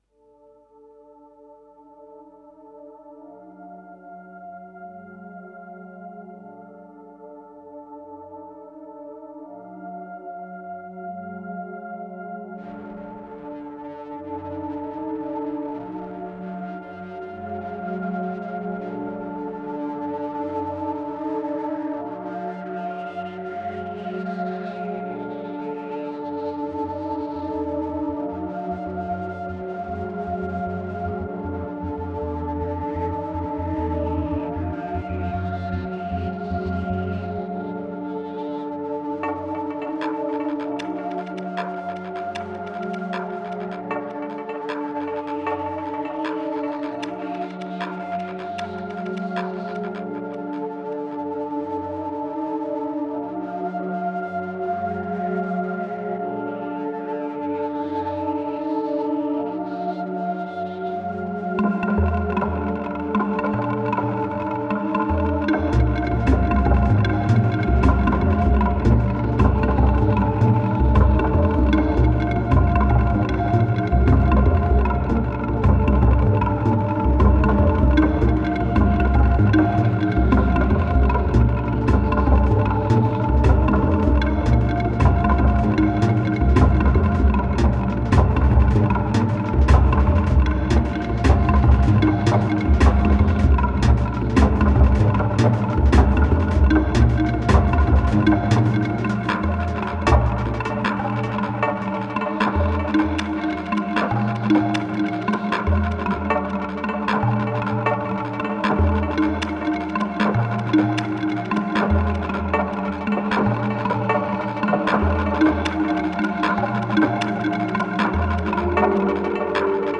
1993📈 - -2%🤔 - 77BPM🔊 - 2011-04-09📅 - -225🌟